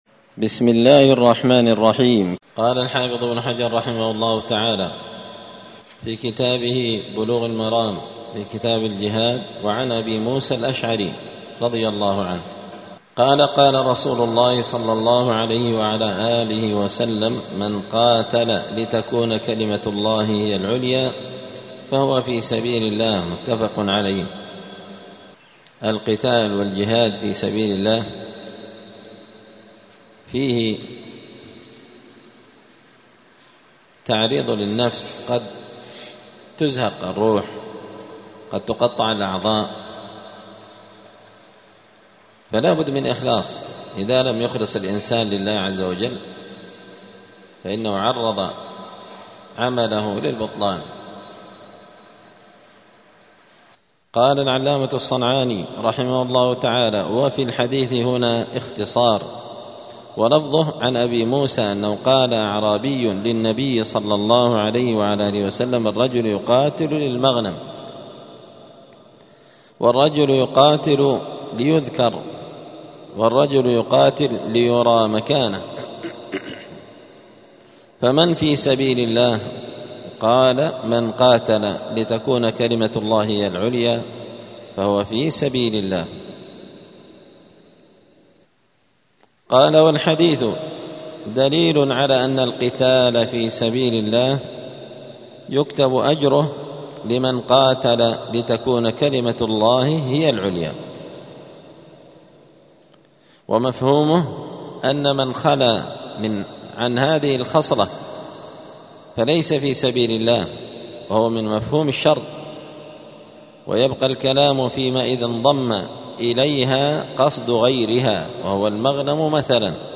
*الدرس الخامس (5) {باب الإخلاص في الجهاد}*